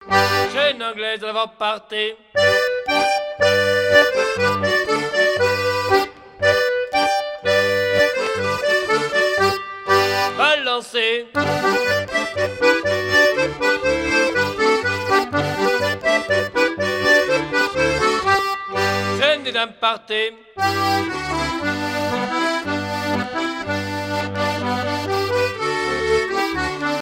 danse : quadrille : chaîne anglaise
Pièce musicale éditée